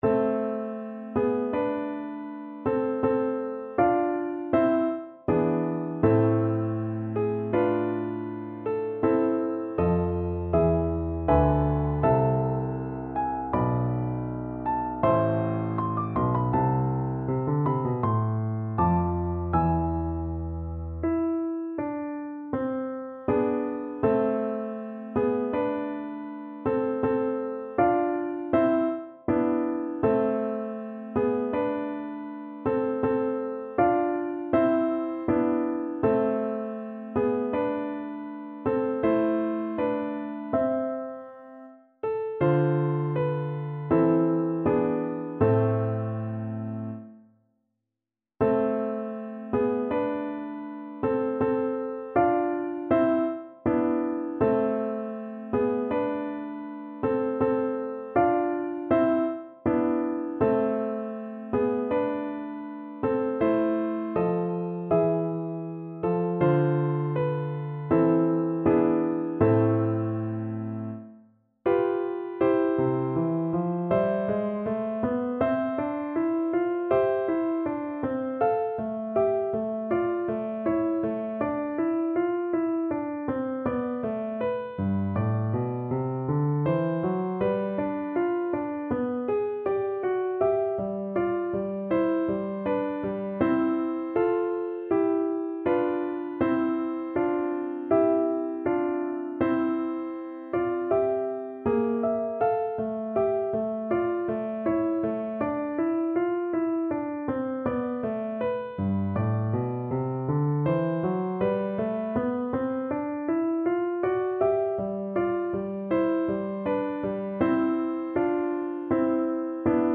Play (or use space bar on your keyboard) Pause Music Playalong - Piano Accompaniment Playalong Band Accompaniment not yet available reset tempo print settings full screen
A minor (Sounding Pitch) (View more A minor Music for Voice )
Andante =c.80
Classical (View more Classical Voice Music)